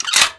hmg_close.wav